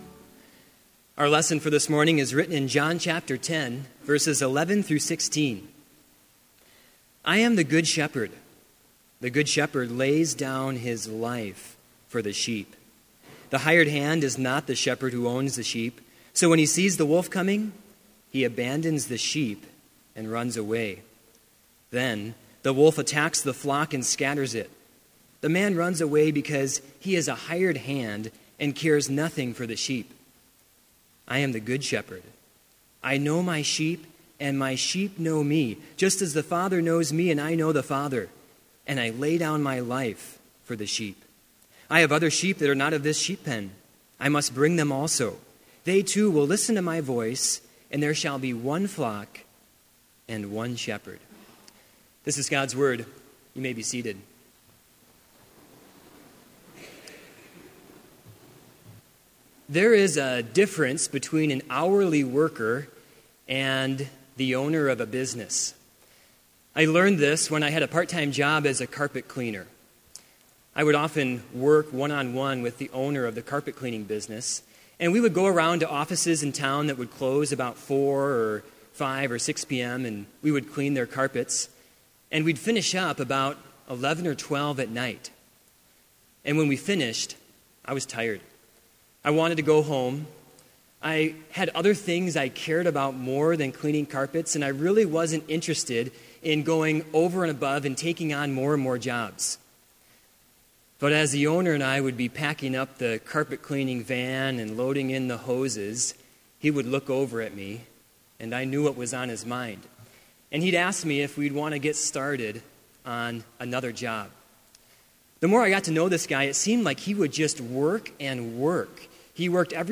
Complete service audio for Chapel - April 11, 2016